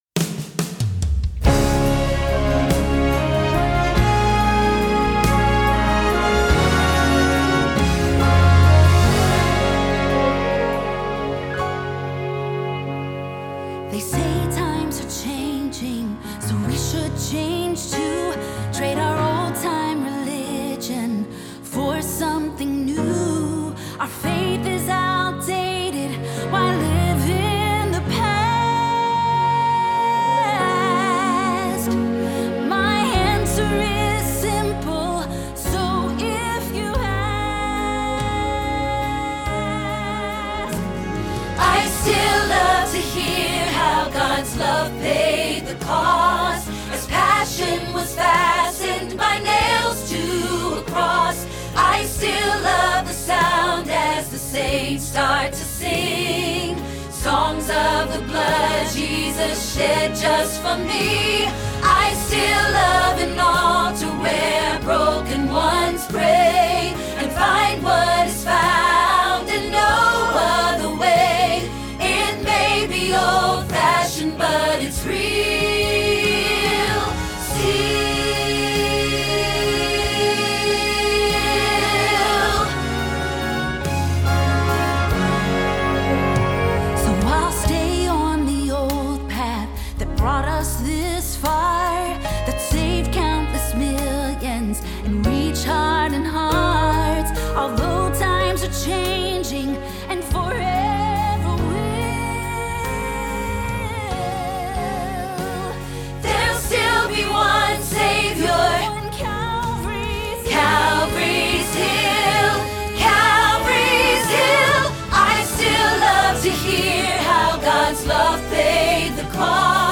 Still – Soprano – Hilltop Choir
Still-Soprano.mp3